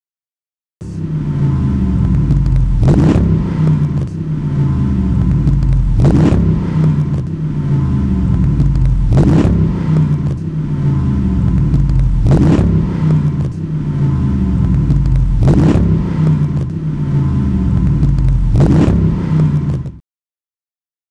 Exhaust is routed through a large-diameter (2.75-inch vs. 2.5-inch) exhaust system with 4-inch chrome tips.
Grand Cherokee SRT8 sound byte (1 MB wav file)